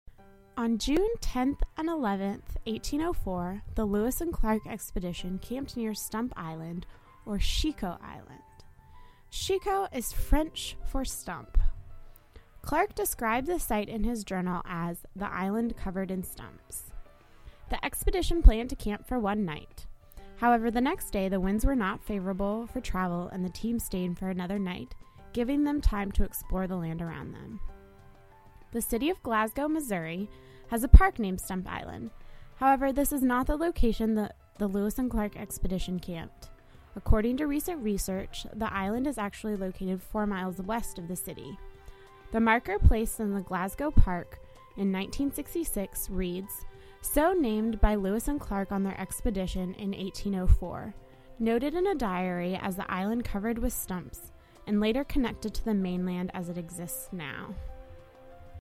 Researched, written, and narrated by University of West Florida Public History Student